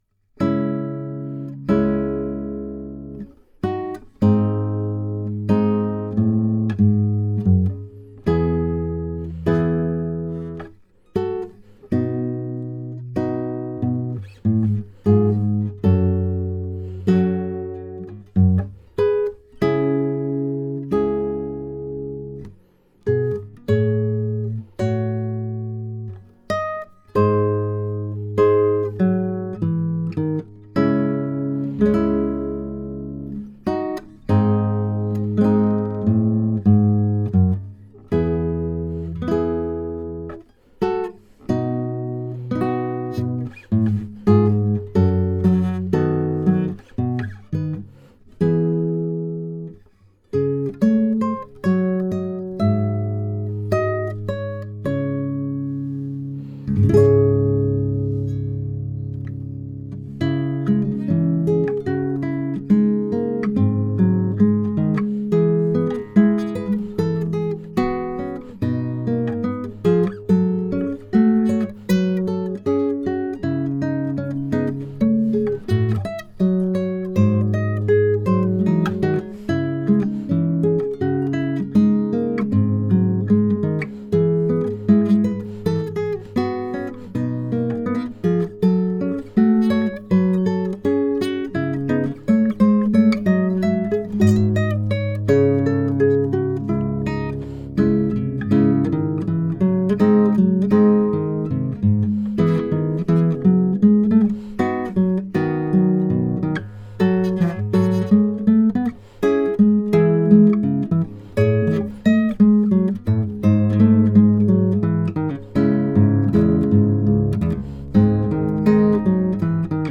Sarabande (Classical Guitar
Це виконання Сарабанди з сюїти HWV 437 Генделя в аранжуванні для класичної гітари, в пониженому на півтону строї, звучить зворушливо та виразно.
Гітарист майстерно передає нюанси фразування й динаміки, надаючи повільному благородному танцю атмосферу спокійної інтимності.
Стримане, але глибоко емоційне виконання, що запрошує до роздумів.